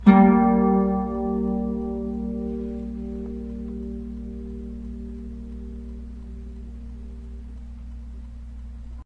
• The Bajo Sexto is a twelve-string guitar.
A Minor chord